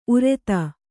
♪ ureta